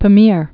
(pə-mîr, pä-)